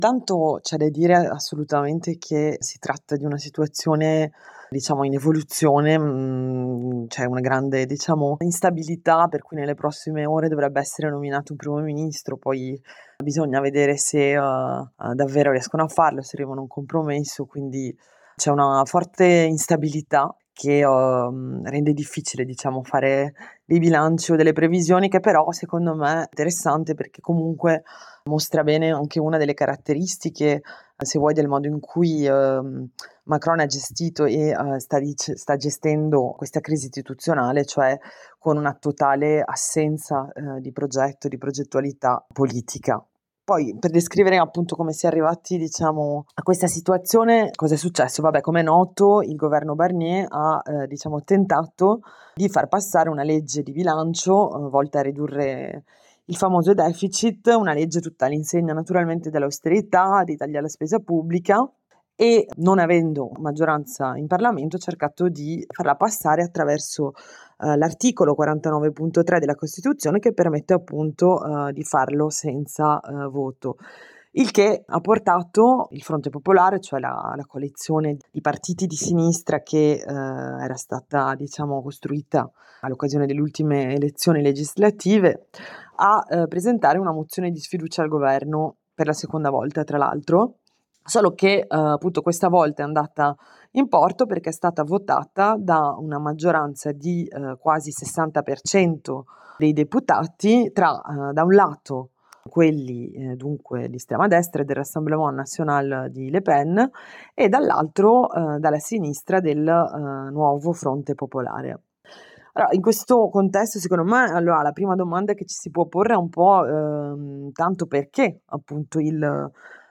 Abbiamo raggiunto una compagna che vive e lavora a Parigi per farci fare un quadro della situazione